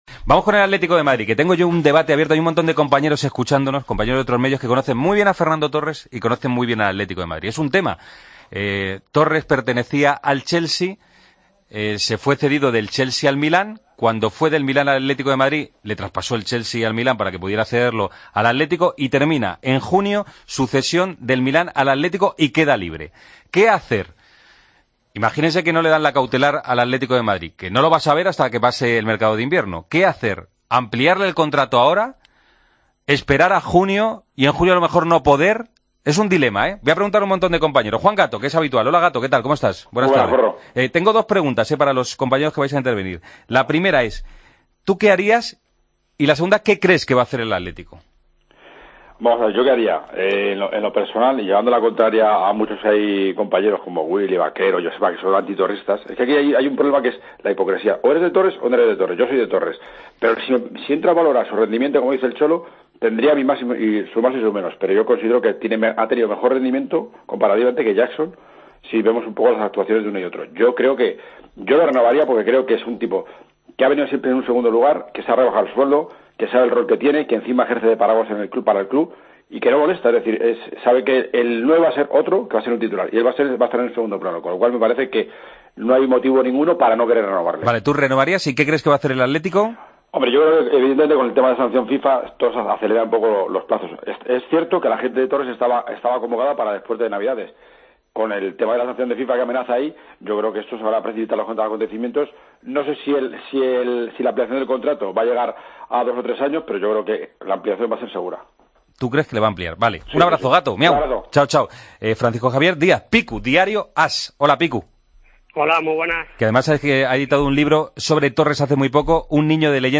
Redacción digital Madrid - Publicado el 20 ene 2016, 16:36 - Actualizado 16 mar 2023, 07:53 1 min lectura Descargar Facebook Twitter Whatsapp Telegram Enviar por email Copiar enlace Debatimos con 5 periodistas que cubren la información del Atlético de Madrid si renovarían a Torres y qué creen que hará el Atlético con el delantero.